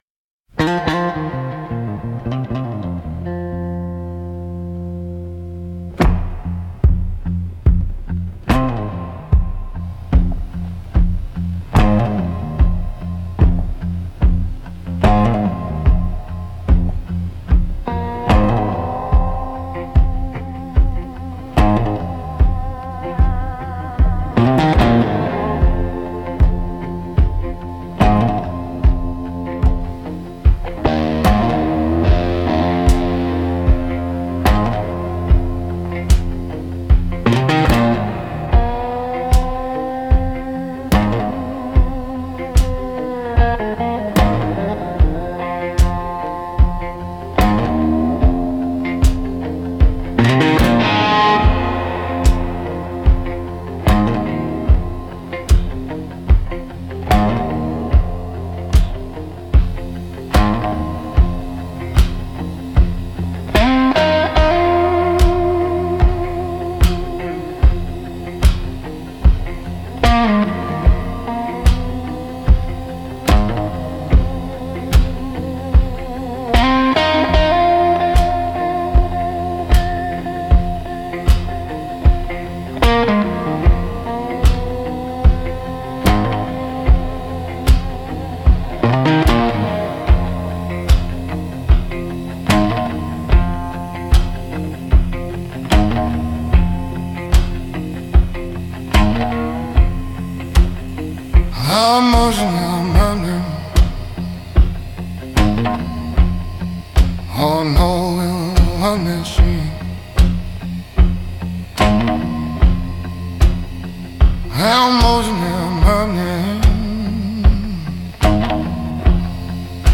Instrumental - Swamp Haze Lament